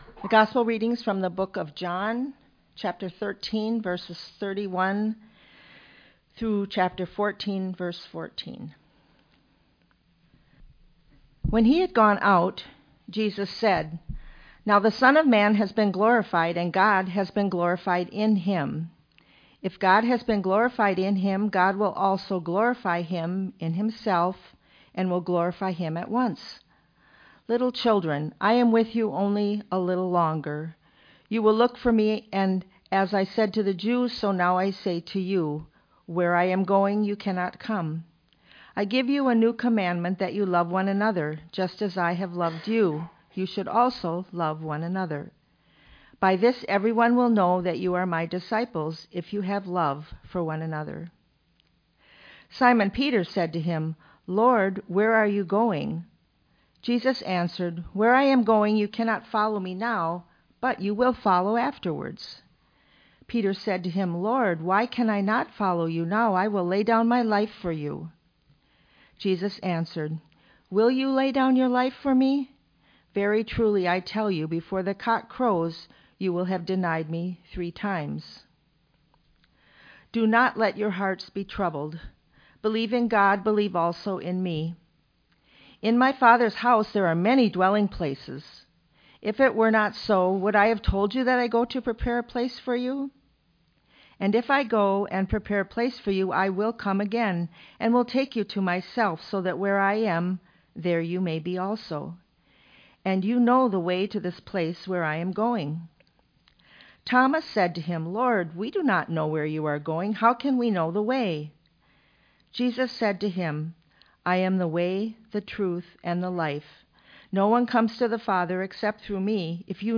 sermon.mp3